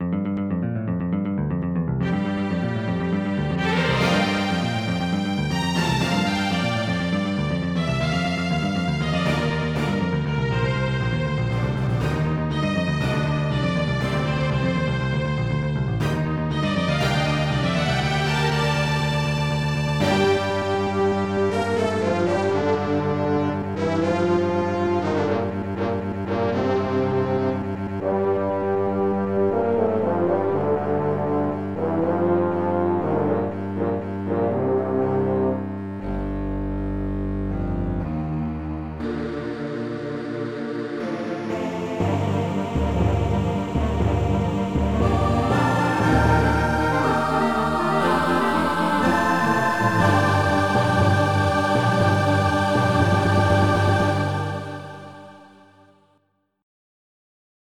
2 channels